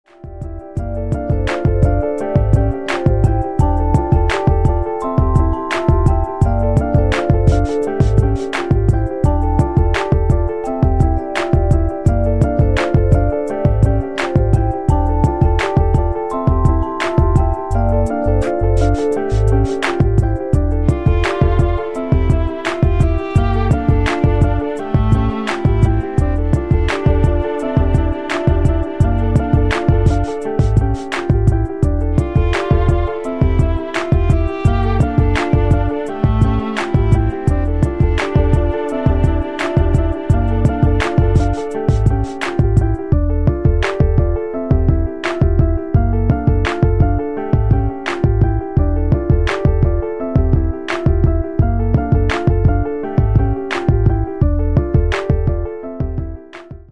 спокойная музыка
Красивая и спокойная музыка со вставками скрипки.